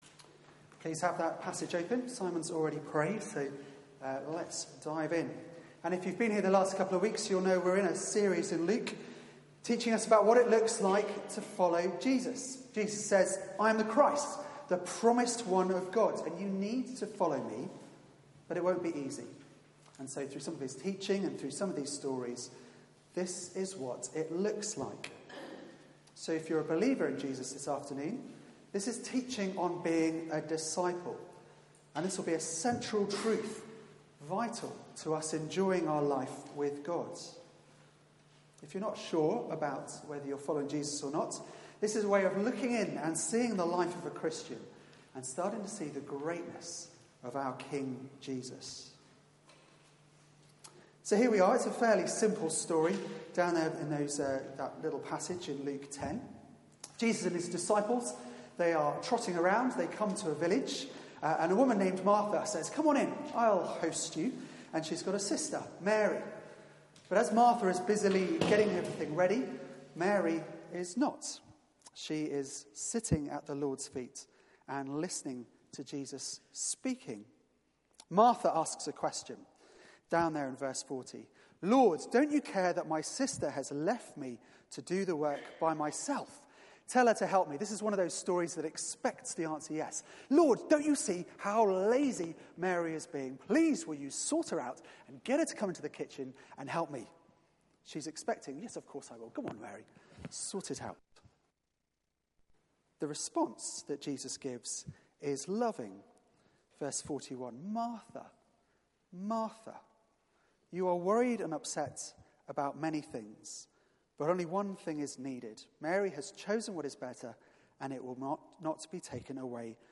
Media for 4pm Service on Sun 10th Mar 2019 16:00 Speaker
Series: Lessons along the Way Theme: Choosing what is better Sermon